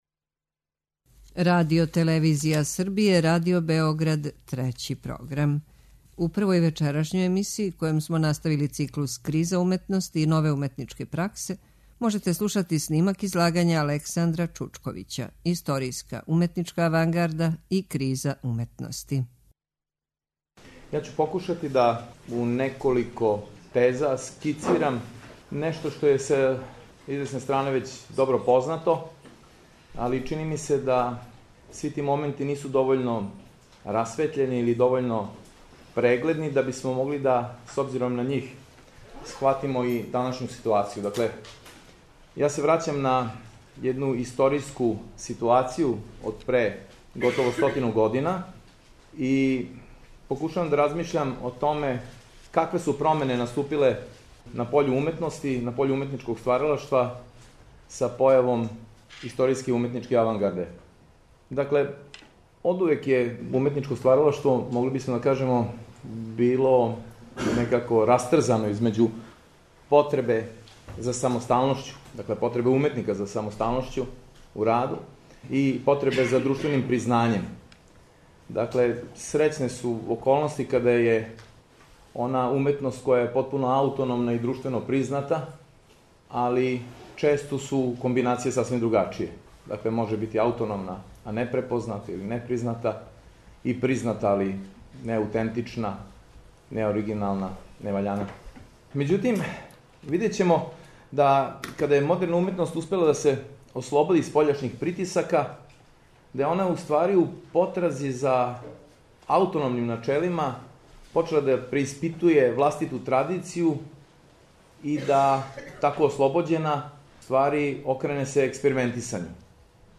Научни скупови